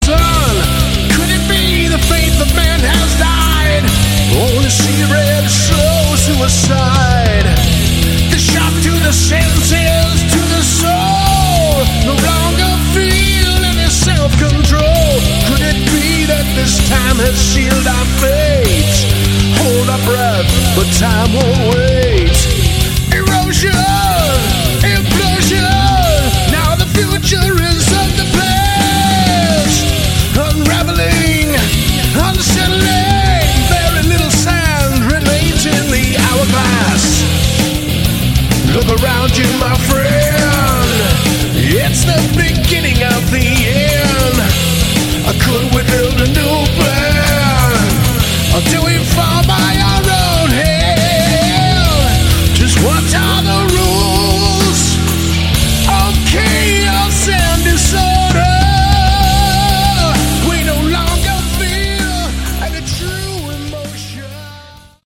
Category: Hard Rock
guitars
vocals